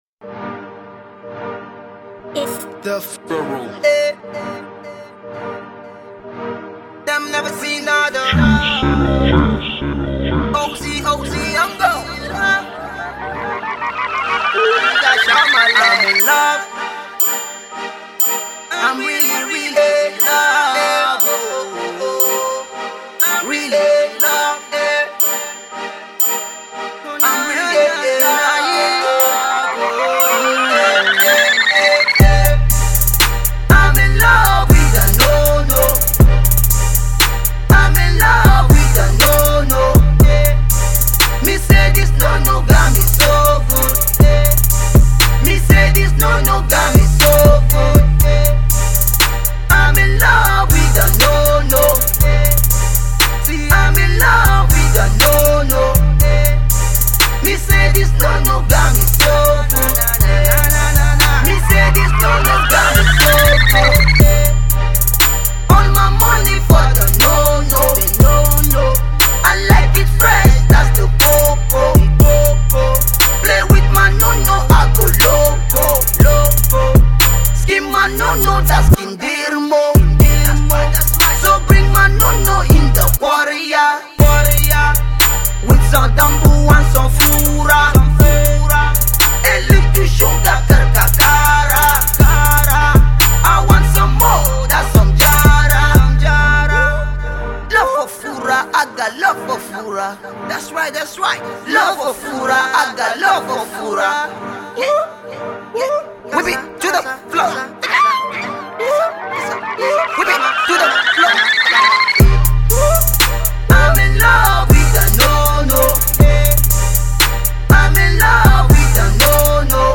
Both MC’s went in